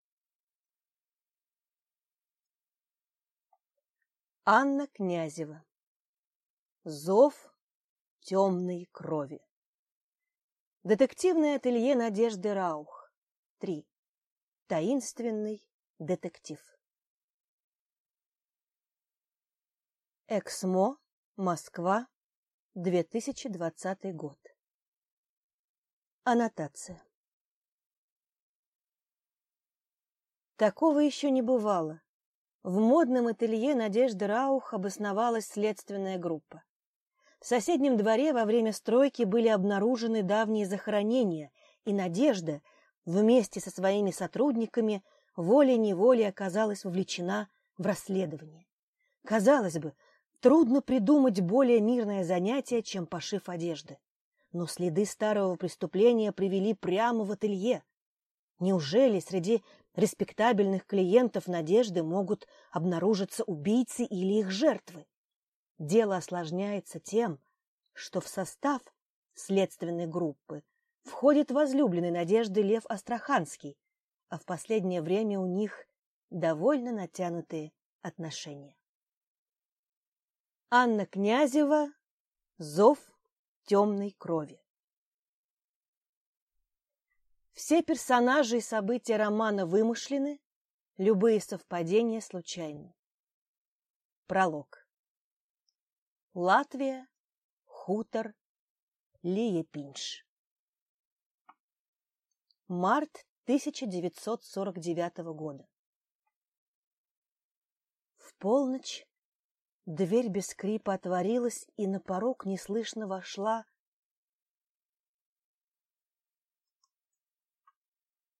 Аудиокнига Зов темной крови | Библиотека аудиокниг